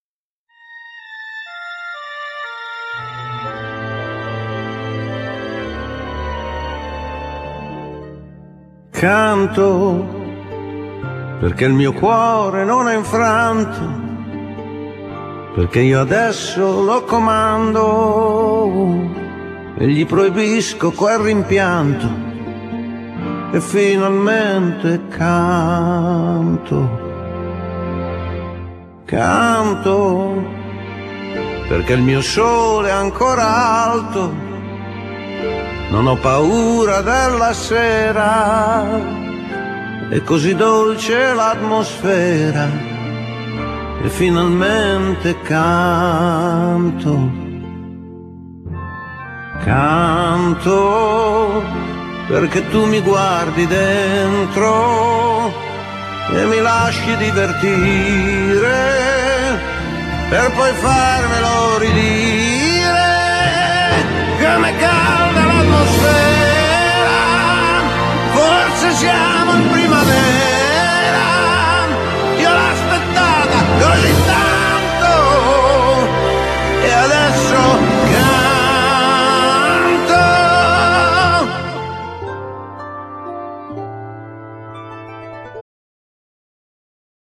Genere : Pop / funky